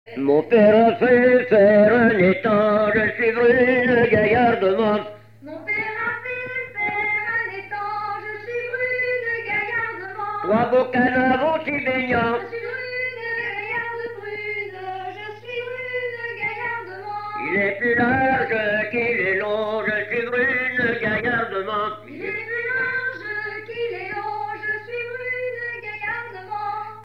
Fonction d'après l'analyste danse : ronde : demi-rond
Genre laisse
Catégorie Pièce musicale inédite